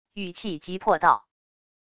语气急迫道wav音频
语气急迫道wav音频生成系统WAV Audio Player